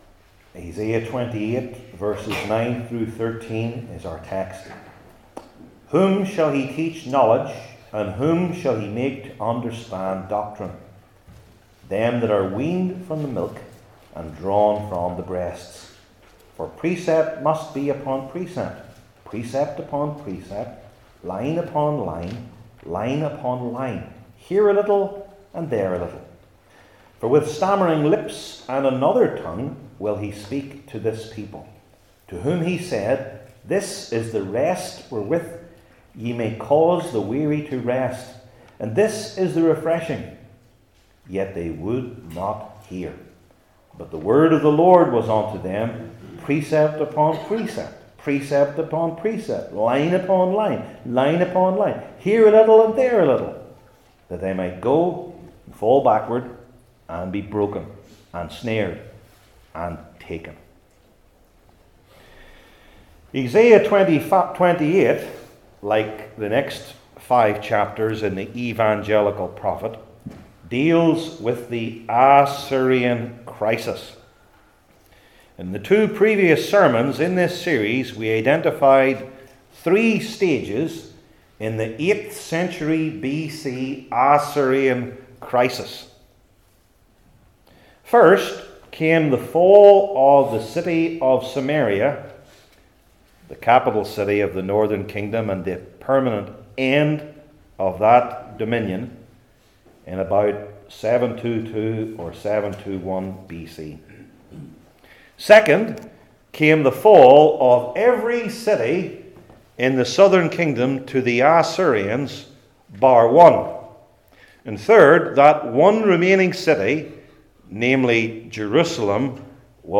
Isaiah 28:9-13 Service Type: Old Testament Sermon Series I. Whom Does God Teach?